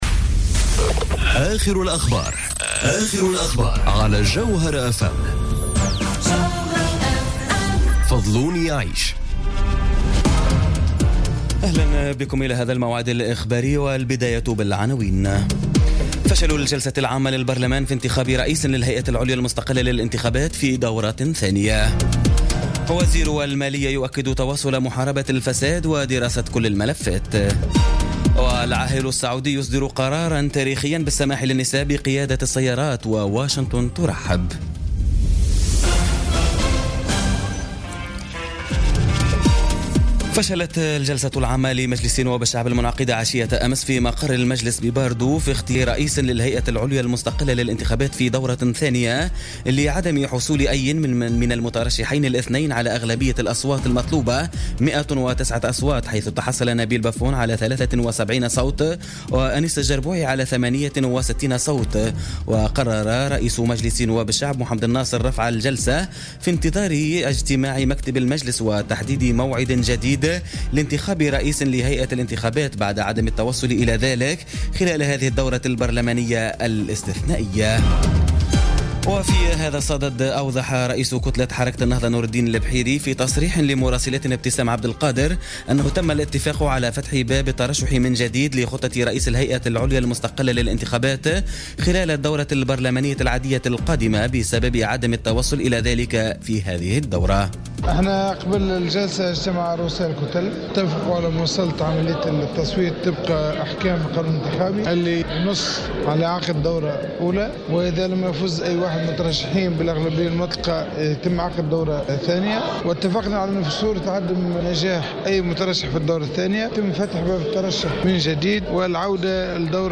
نشرة أخبار منتصف الليل ليوم الاربعاء 27 سبتمبر 2017